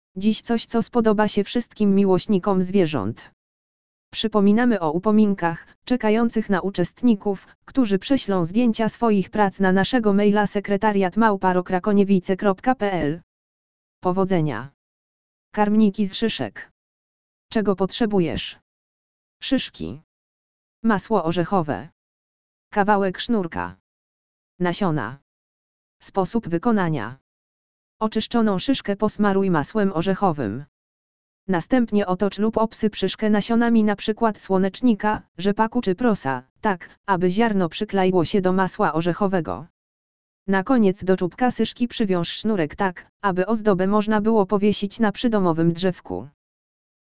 audio_lektor_karmniki_z_szyszek.mp3